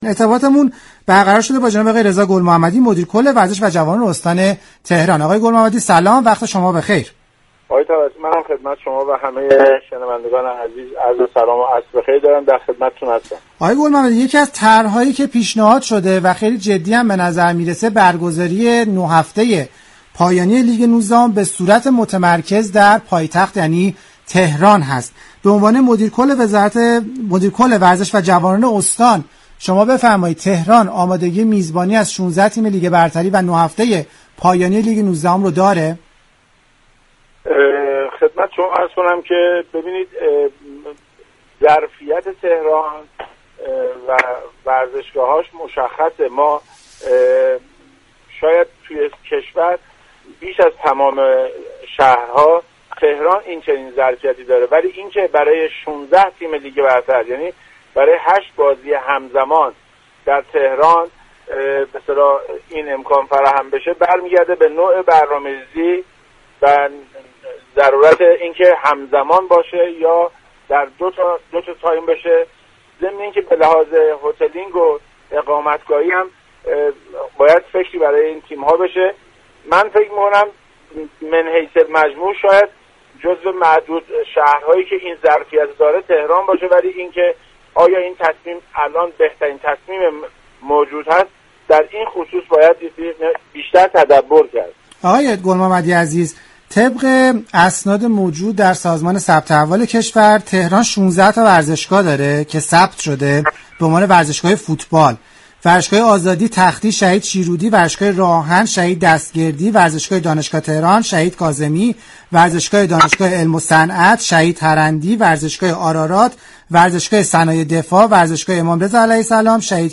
برنامه زنده